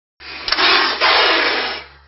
Category: Animals/Nature   Right: Personal